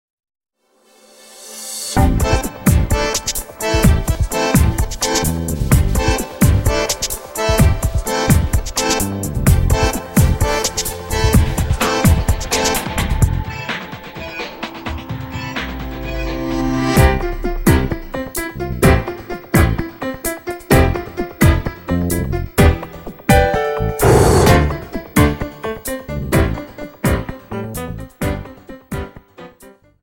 Tango 32 Song